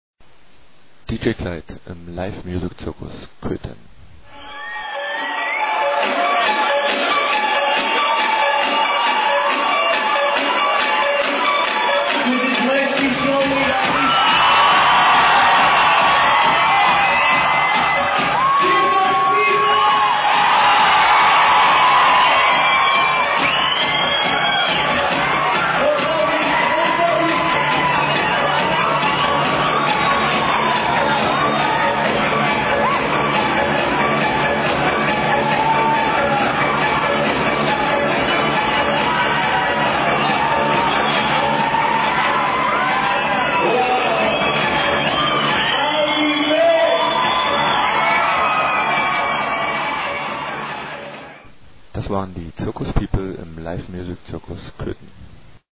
was played in local club this weekend (source from there)
cant really hear very well!! got a better rip??